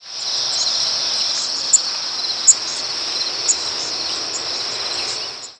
Blackpoll Warbler diurnal flight calls
Bird in flight.